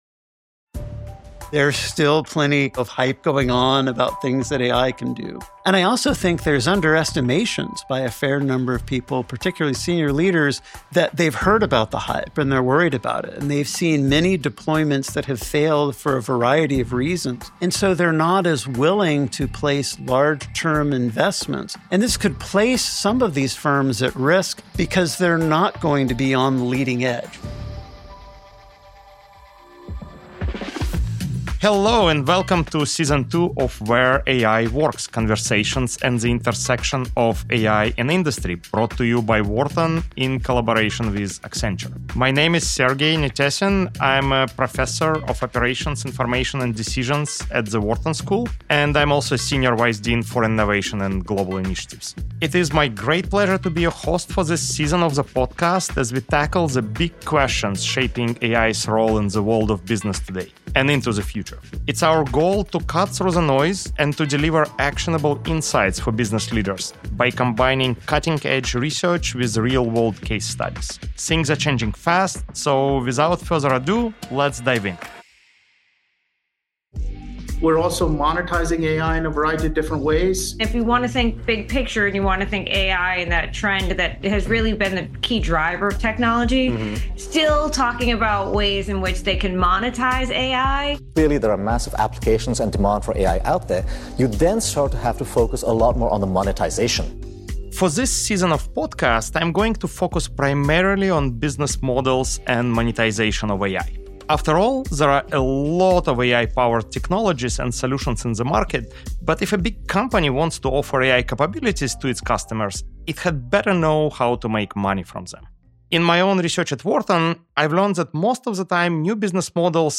Where AI Works: Conversations at the Intersection of AI and Industry · S2 E1 AI, Hardware, and the Future of Intelligent Systems Play episode June 26 25 mins Bookmarks Episode Description How can artificial intelligence not just transform industries but also create real business value?